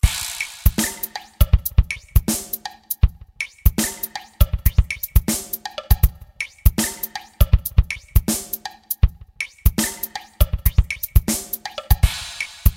分かる人は分かると思いますが、このようなスネアの音はSCのどこを探してもありません(ニヤリッ)
あの手この手で誤魔化したスネア音です。 もちろんSC-8820しか使ってませんよ。